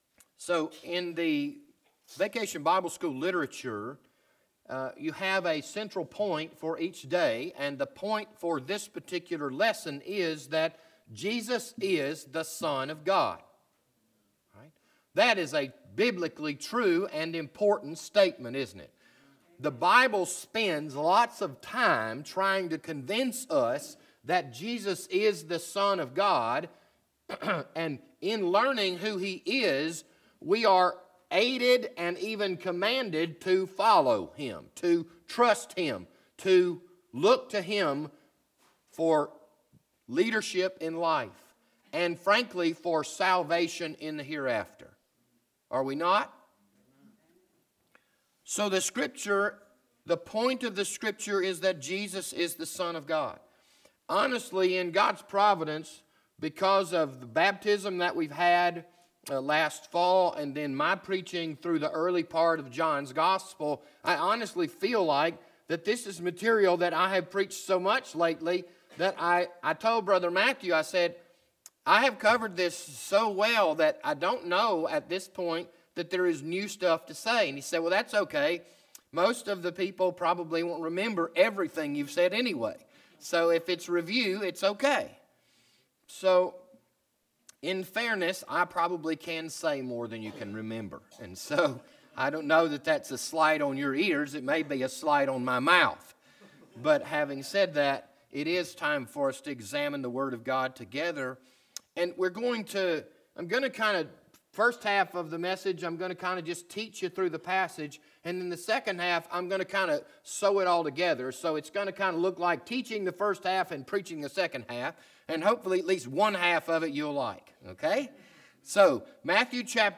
This Sunday morning sermon was recorded on May 12, 2019.